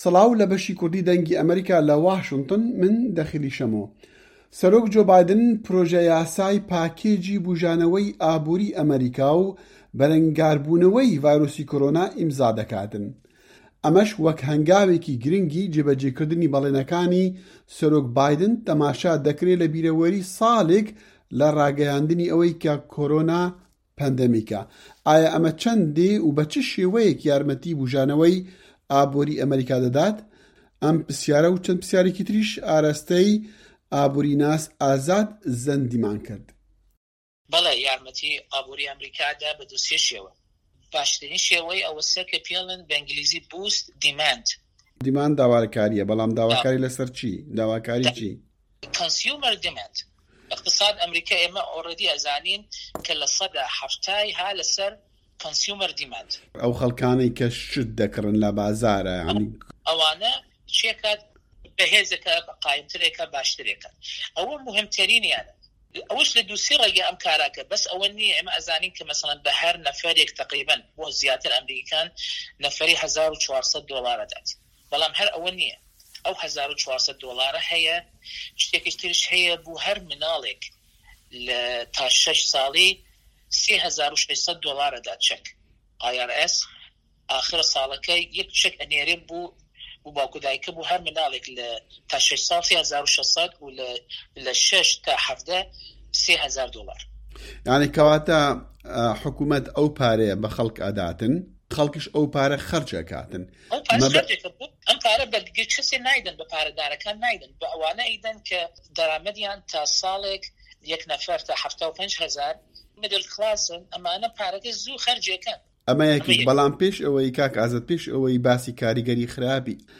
ئه‌مه‌ریکا - گفتوگۆکان